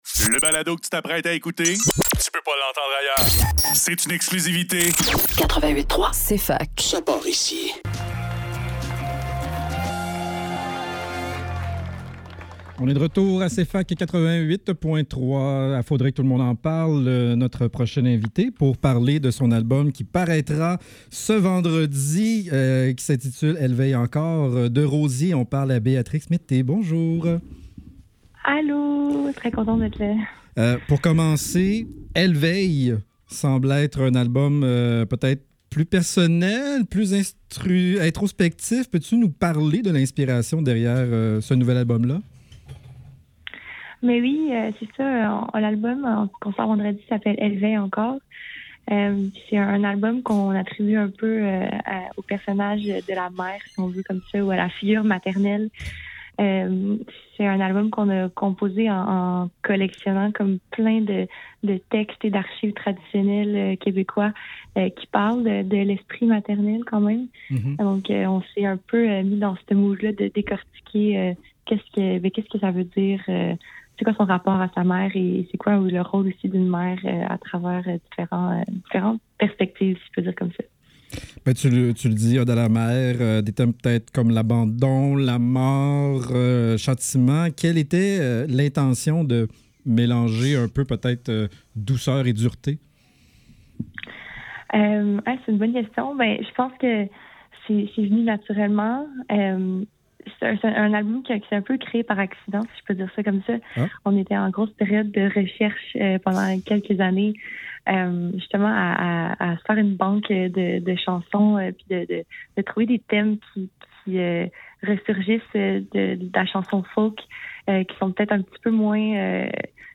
Faudrait que tout l'monde en parle - Entrevue avec Rosier